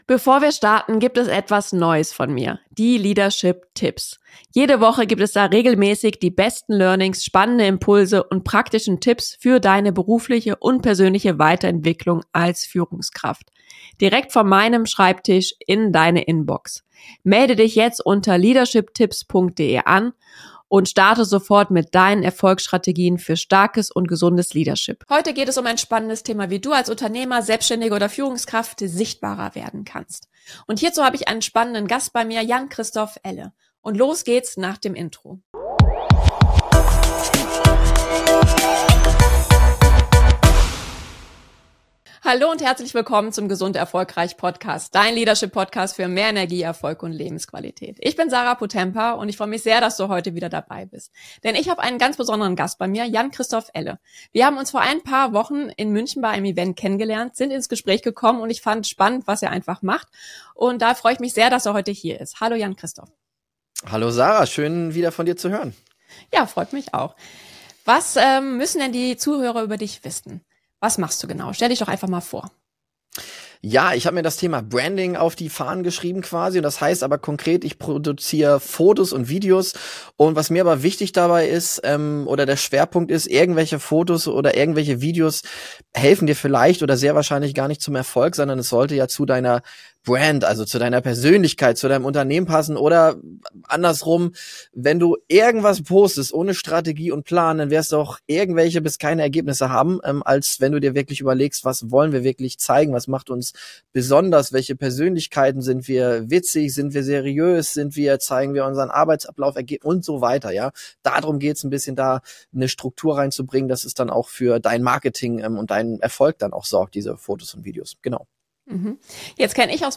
Nr. 54 - Personal Branding: Wie du dich als Nr. 1 in deinem Gebiet positionierst! - Interview